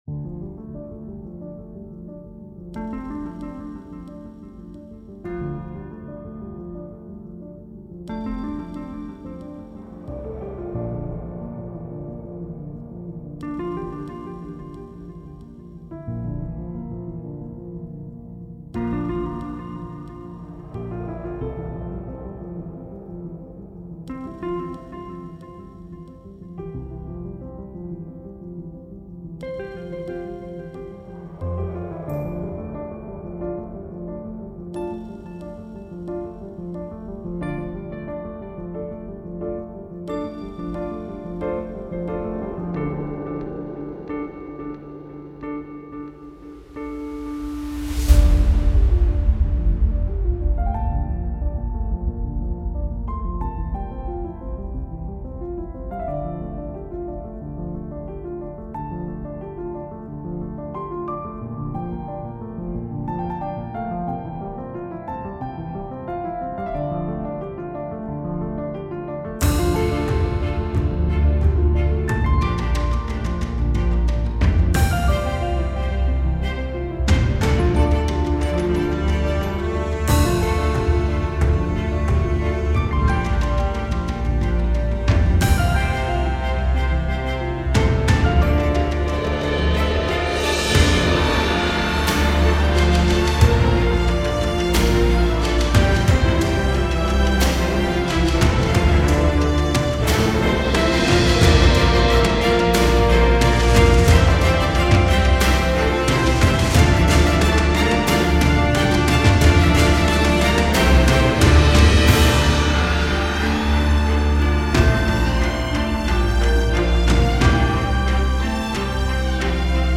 Давят на уши треки